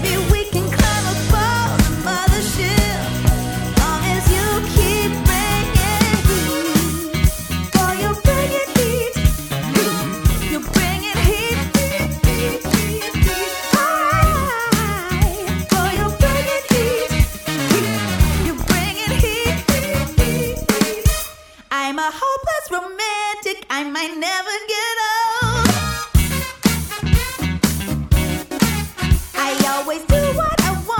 chant...